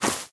Minecraft Version Minecraft Version snapshot Latest Release | Latest Snapshot snapshot / assets / minecraft / sounds / block / roots / step2.ogg Compare With Compare With Latest Release | Latest Snapshot
step2.ogg